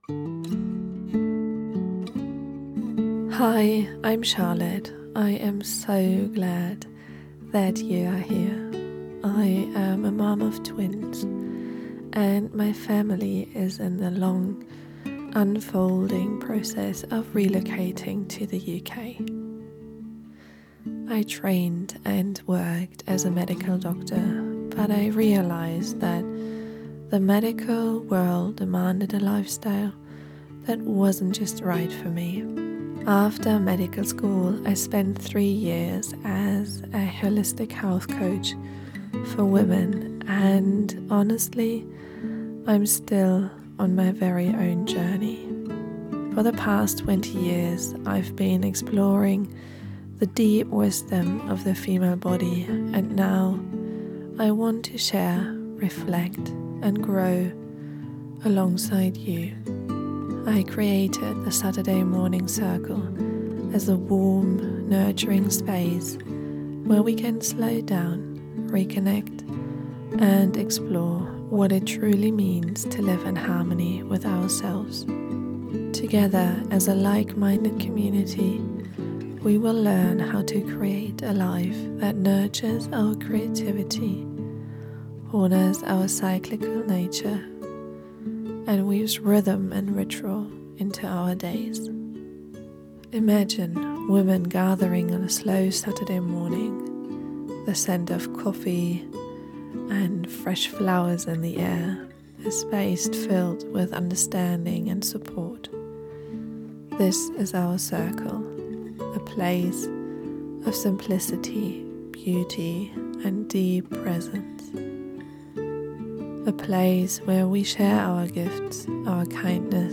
Through personal storytelling, playful musings, and relaxed solo episodes, we’ll dive into what it means to live intentionally, nurture our intuition, and build community in a fast-paced world.